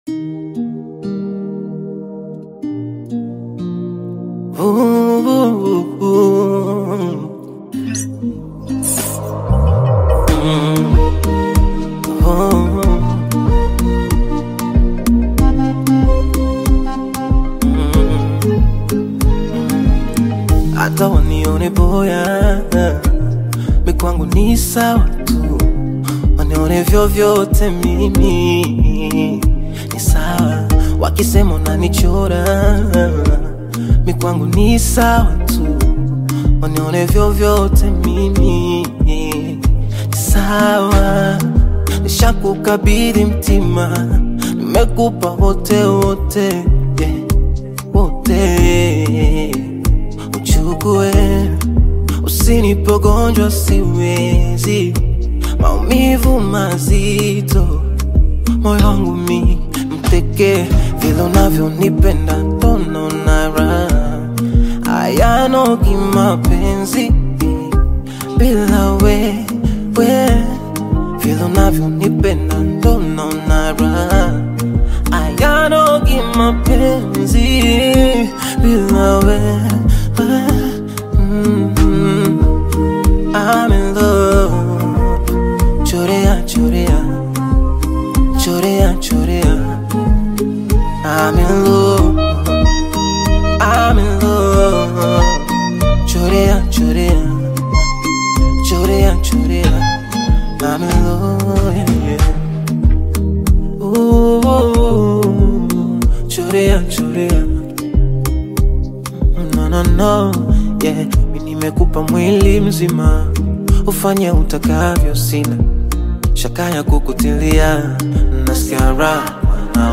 uplifting Afro-Pop single